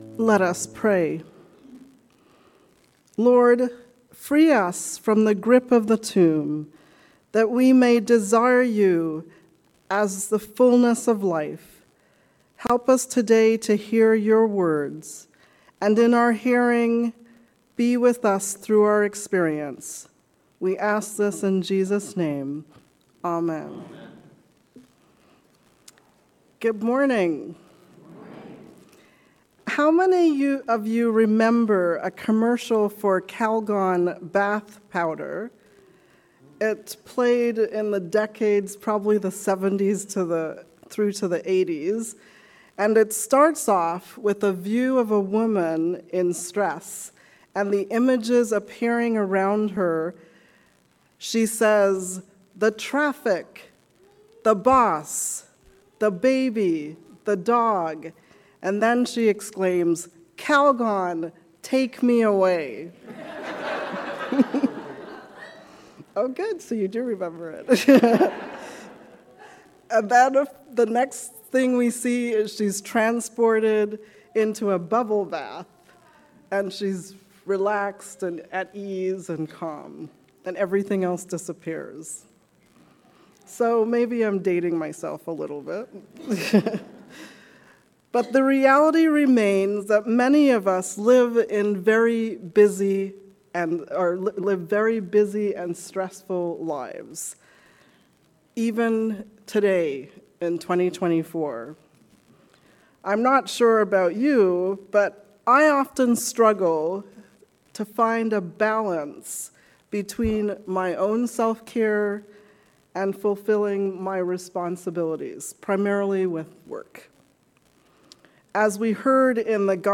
Sermon for the Ninth Sunday after Pentecost